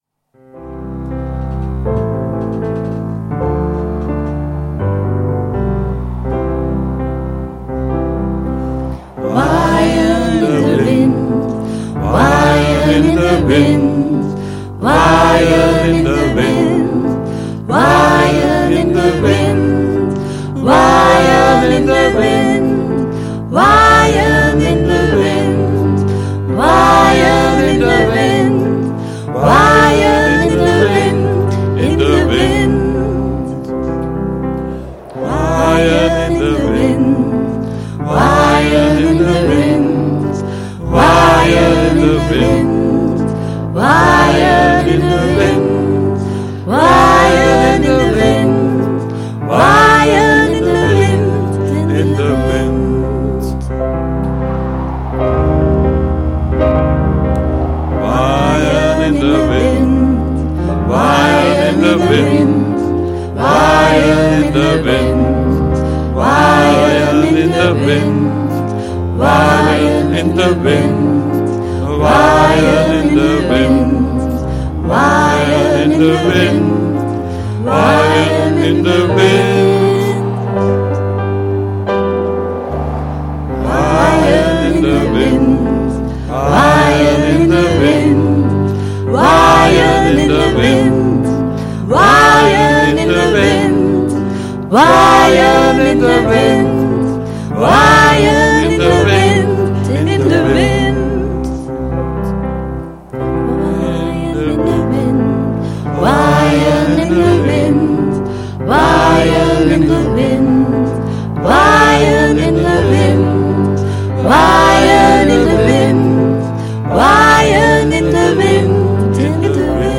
Muziek en liedje bij de voorstelling
waait_zingen_met_4.mp3